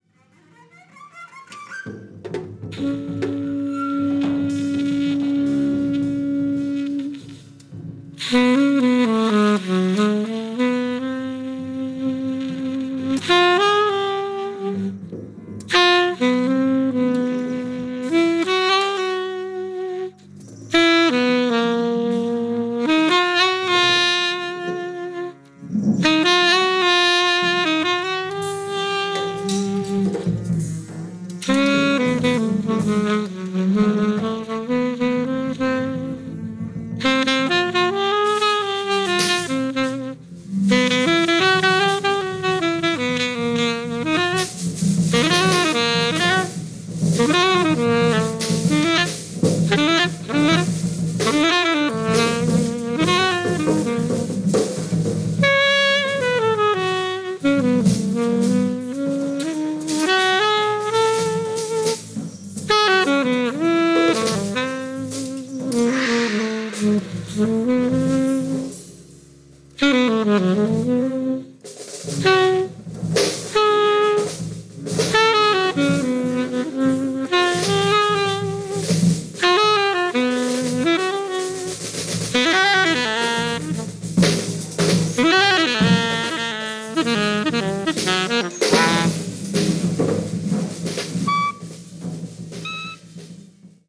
Alto Saxophone
Bass
Drums